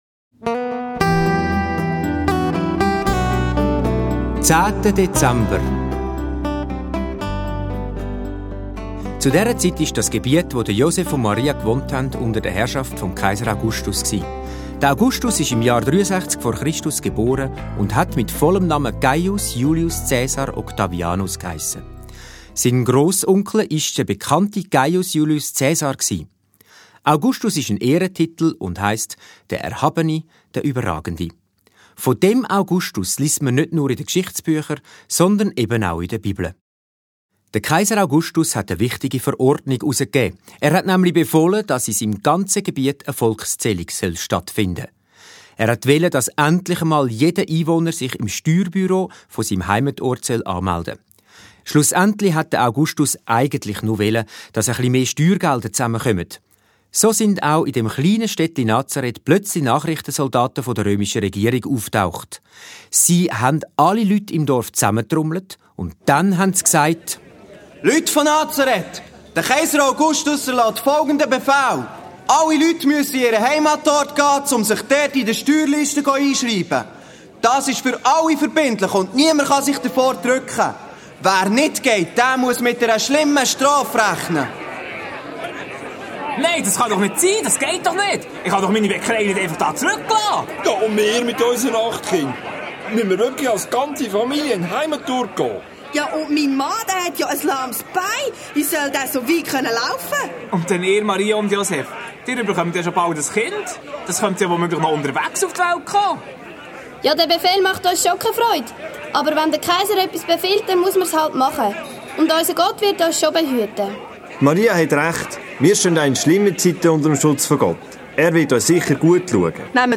- 24 Hörspiele
Hörspiel-Album (ohne Kalender)